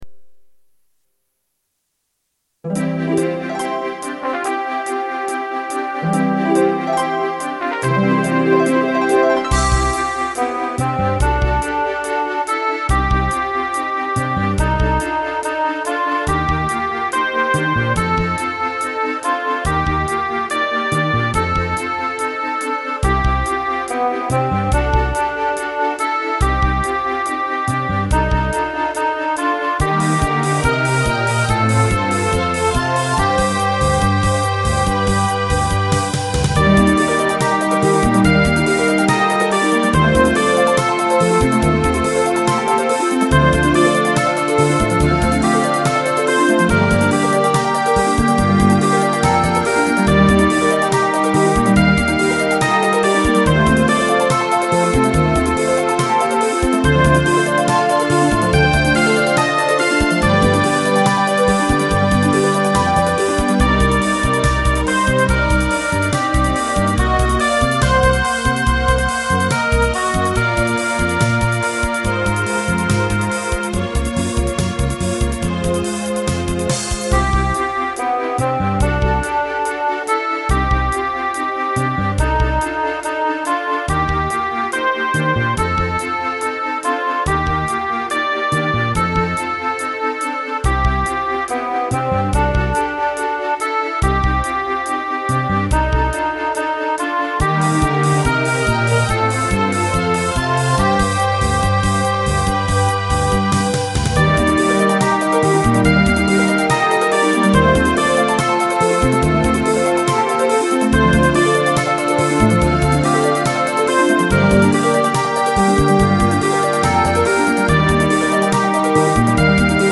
管理人が作ったMIDI集です